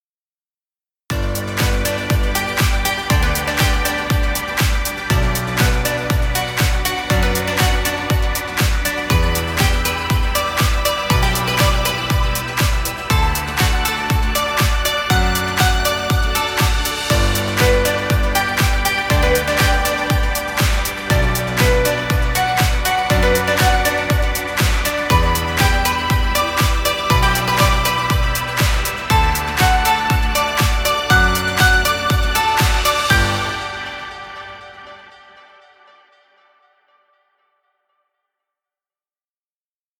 Happy upbeat music.